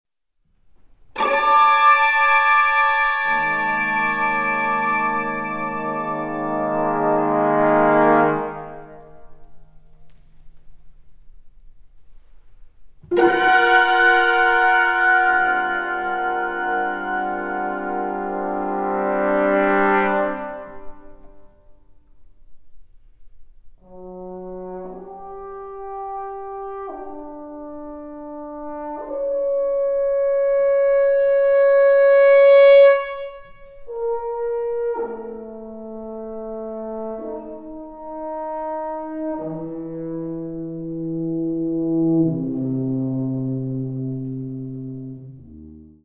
Poco andante (with tension and suspense)
Recorded in the Presence of the Composer